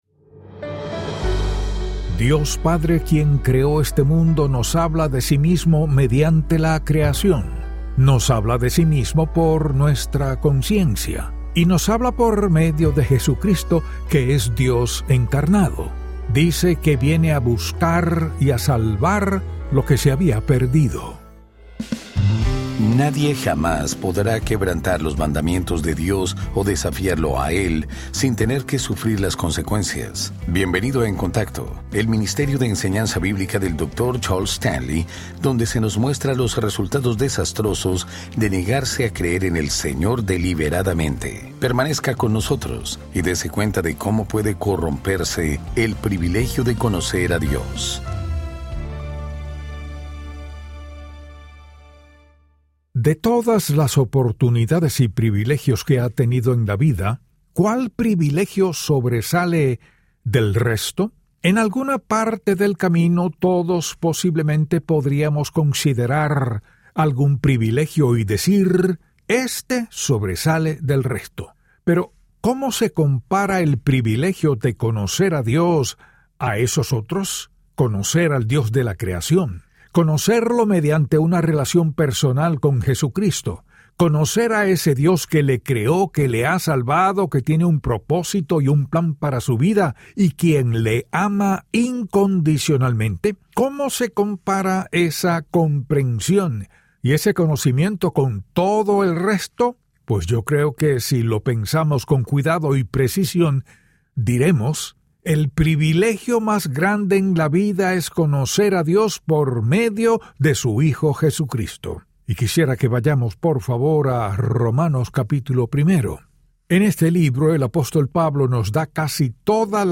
Dr. Charles Stanley y el programa diario de radio In Touch Ministries.